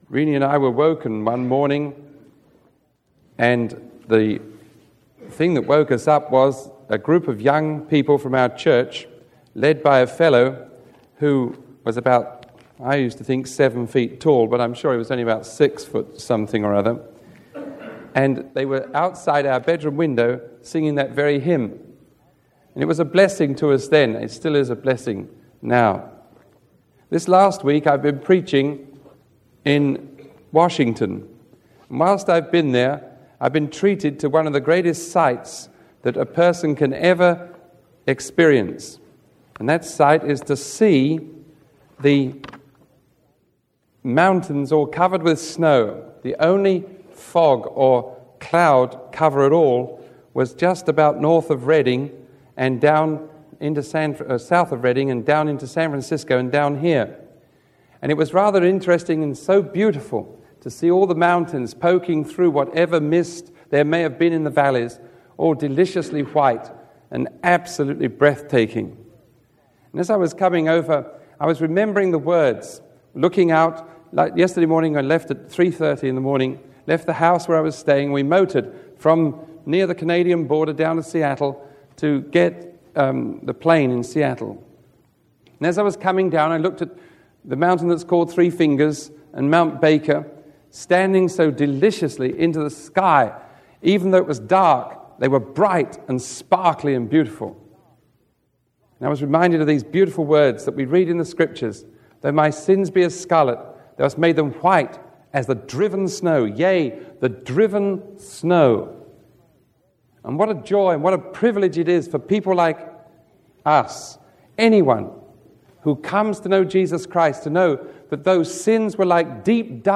Sermon 0840A recorded on January 15